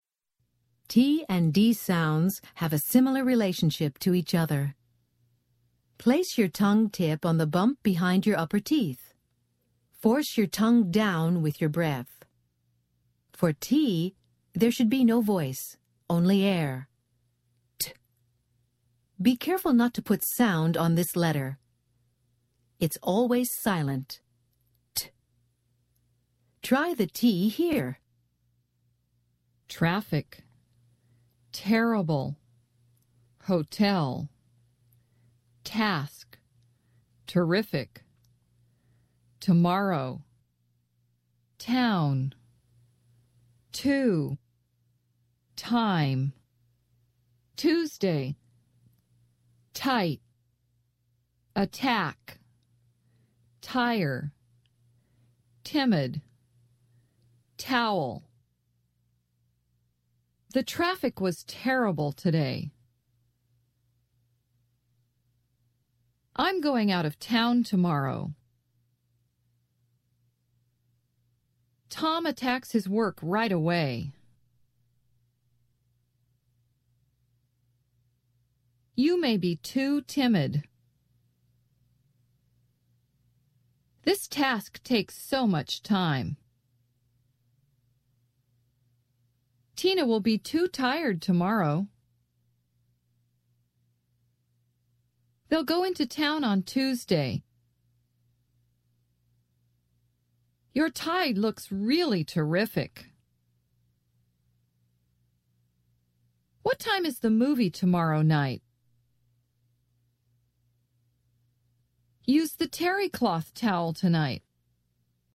American Phonics Lesson 32 - Consonant /t/